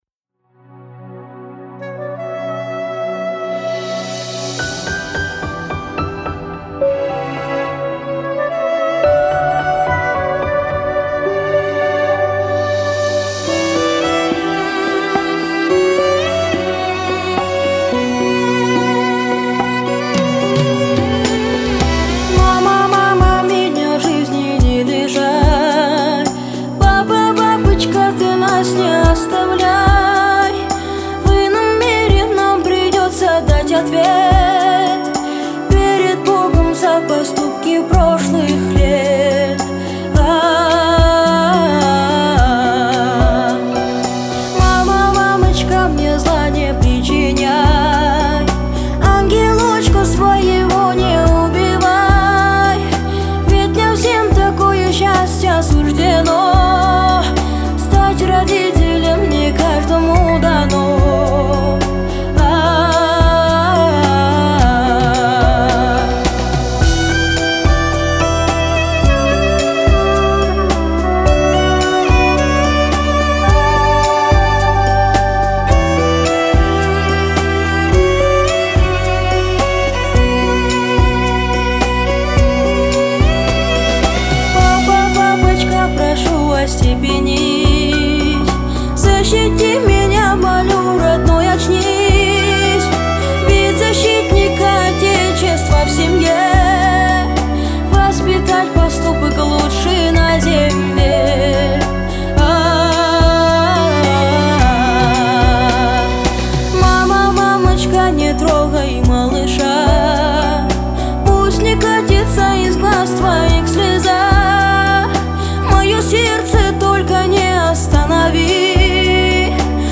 романтичная поп-баллада